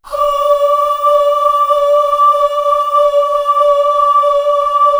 Index of /90_sSampleCDs/Best Service ProSamples vol.55 - Retro Sampler [AKAI] 1CD/Partition C/CHOIR AHH